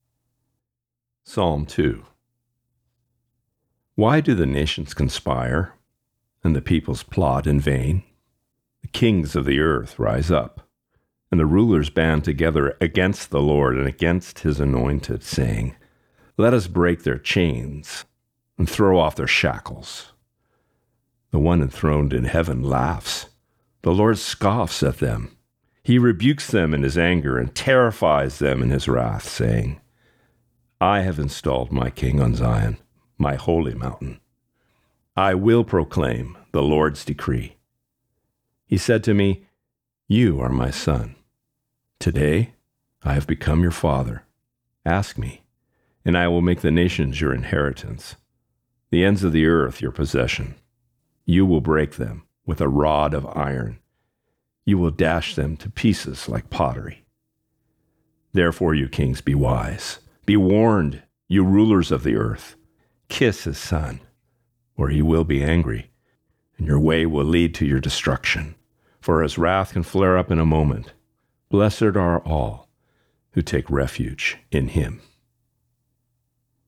Reading: Psalm 2